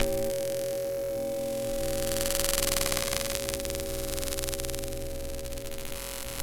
Звуки глитч-эффектов
Помехи в проигрывателе